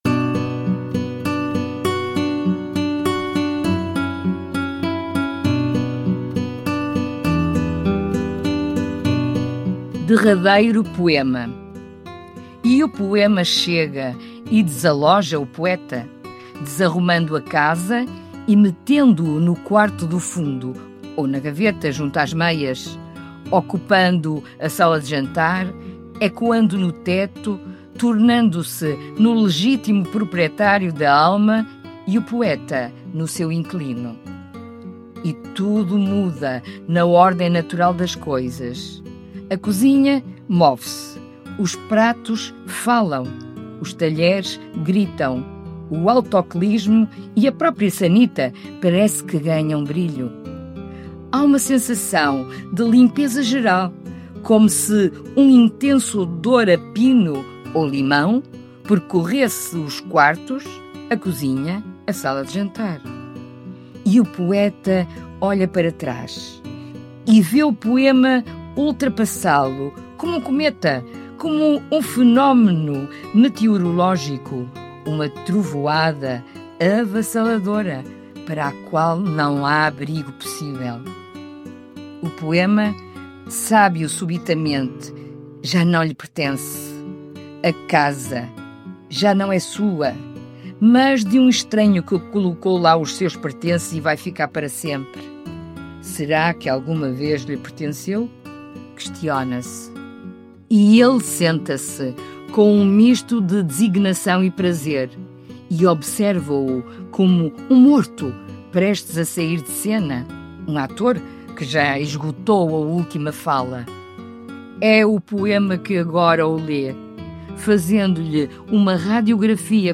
Música: Classical Acoustic Guitar, por EpicMann, Licença Envato – Free Files Single Use Policy.